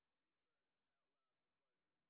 sp04_street_snr0.wav